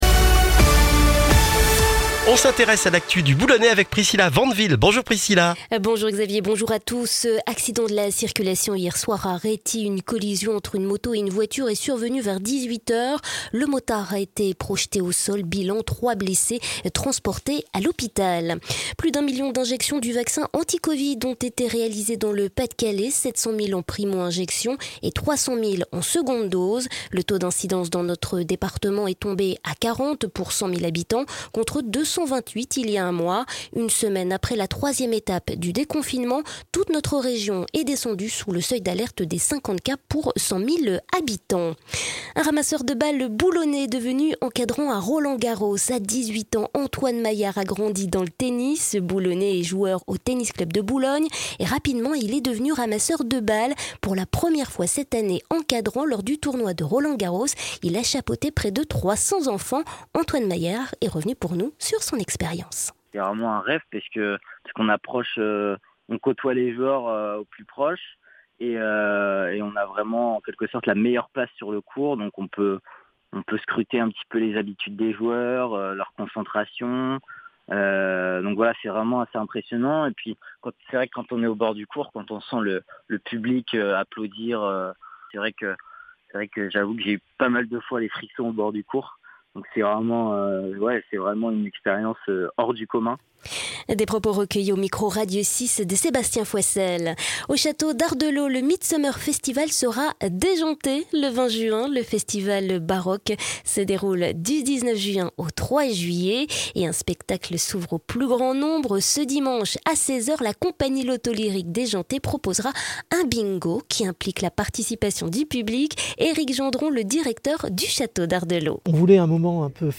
Le journal du mercredi 16 juin dans le Boulonnais